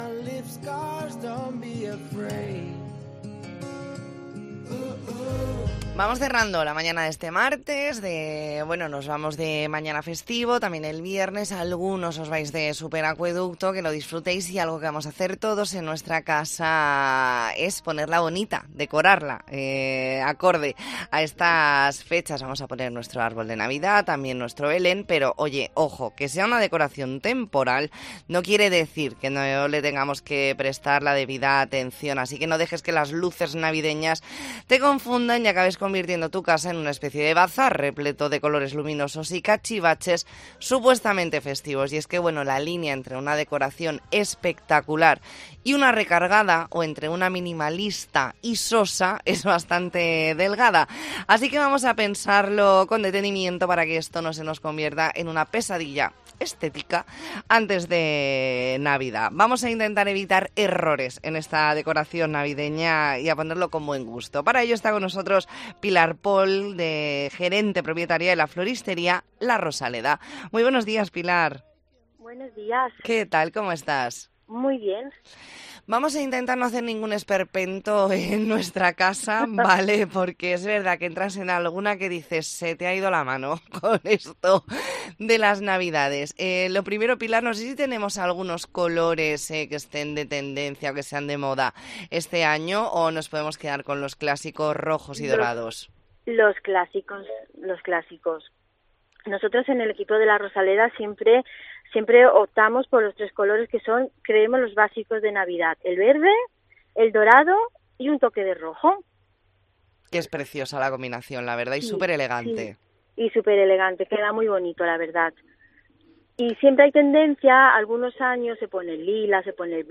Entrevista en La Mañana en COPE Más Mallorca, martes 5 de diciembre de 2023.